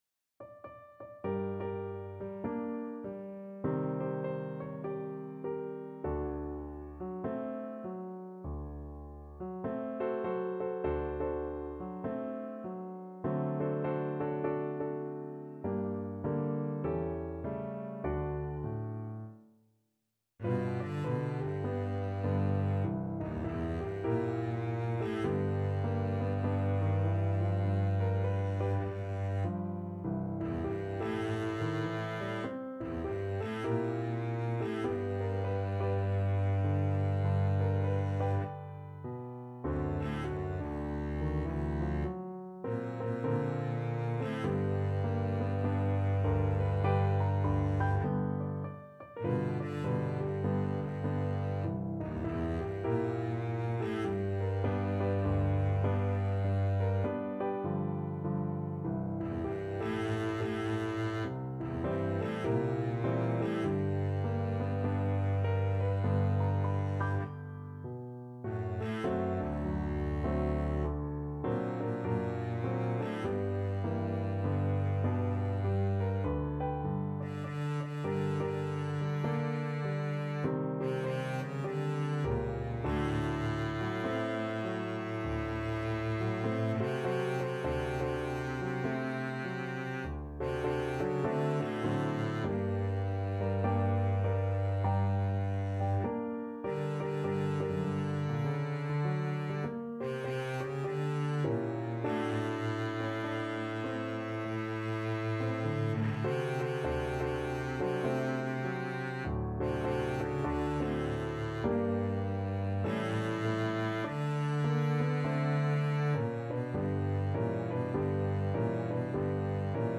4/4 (View more 4/4 Music)
Jazz (View more Jazz Double Bass Music)